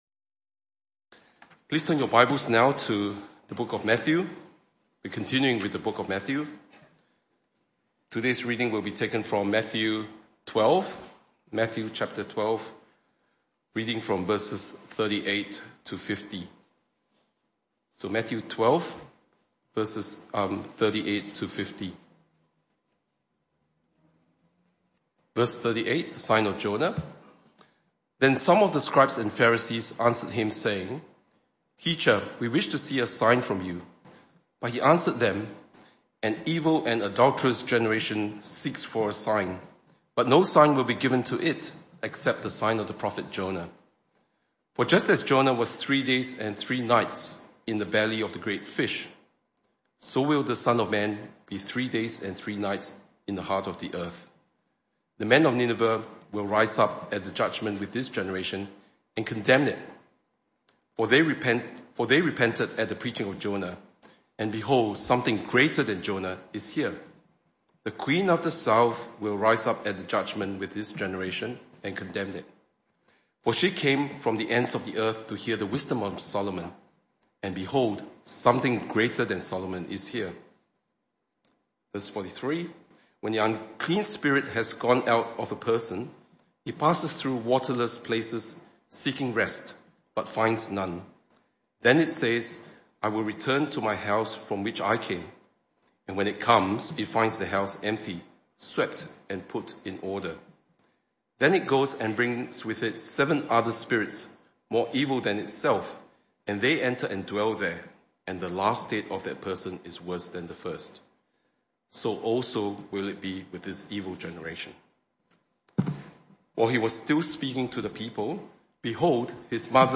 This talk was part of the AM Service series entitled The Message Of Matthew.